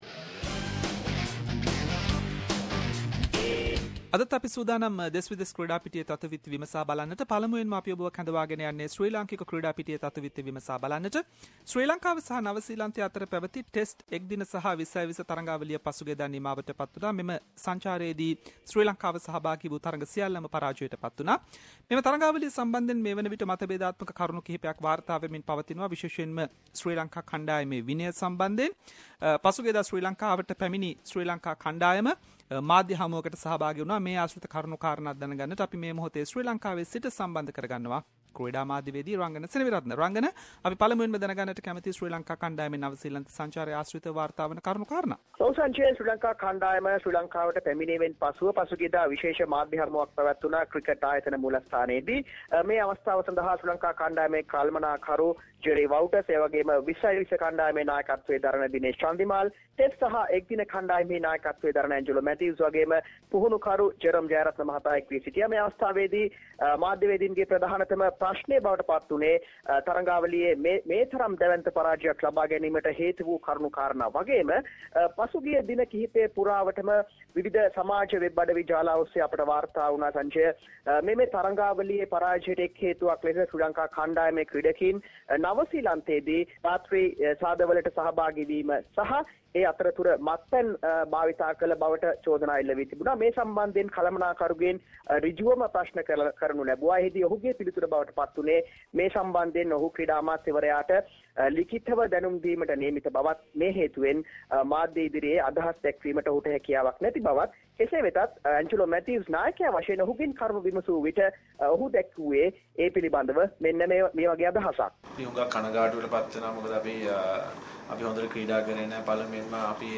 In this weeks SBS Sinhalese sports wrap…. Controversy related Sri Lanka cricket tour to New Zealand players and officials response to that, New position for former Sri Lanka cricket coach Marvan Atapattu and many more sports news.